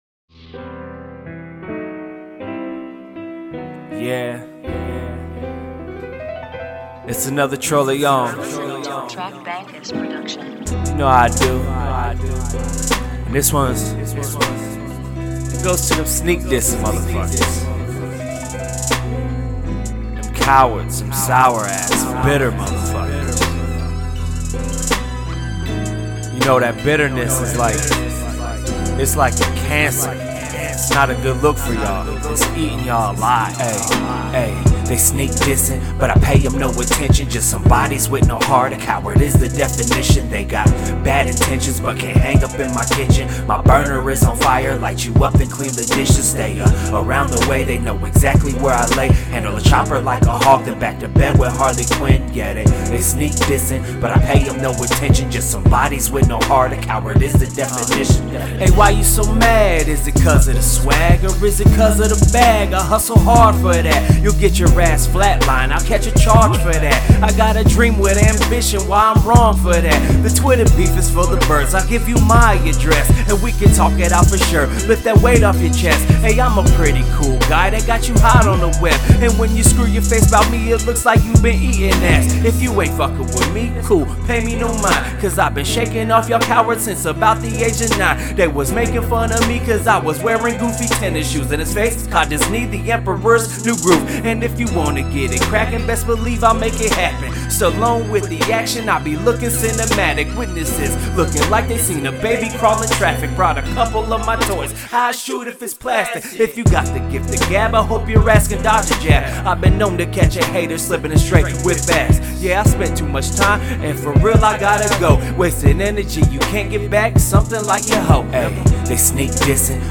Hiphop
Description : OHIO Hip-Hop Muzik!!!